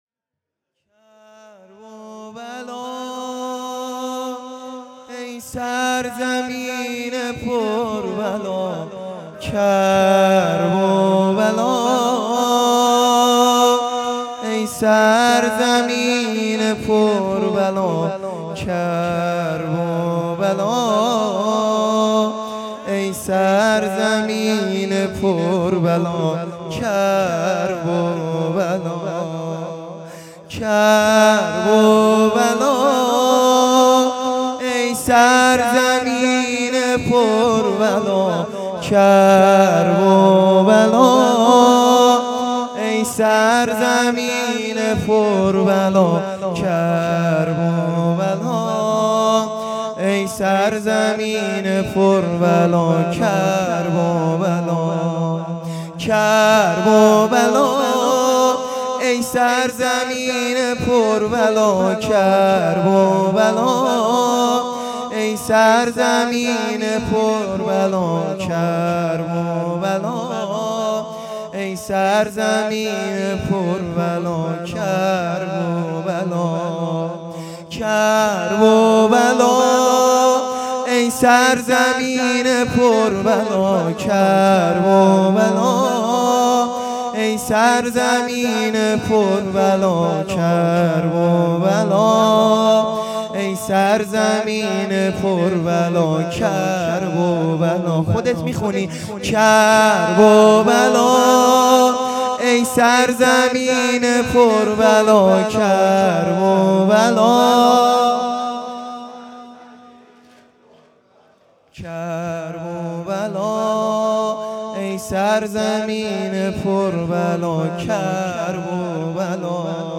زمینه | کرب بلا ای سرزمین مادری